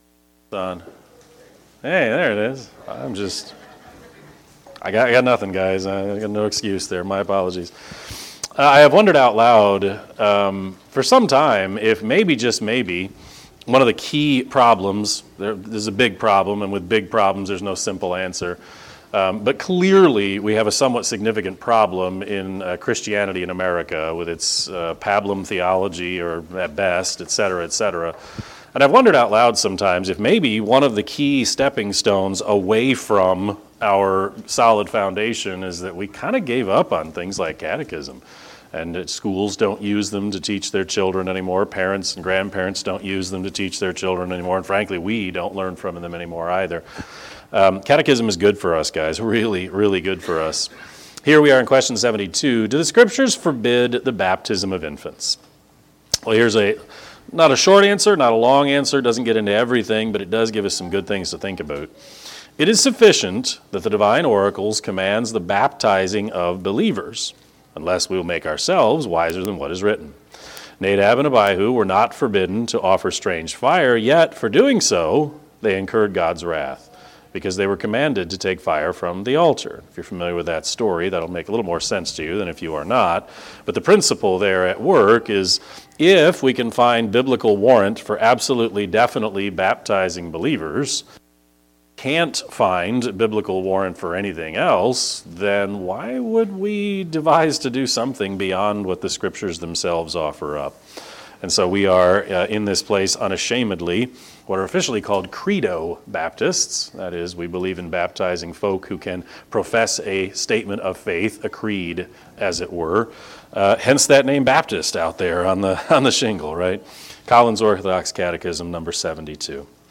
Sermon-5-7-23-Edit.mp3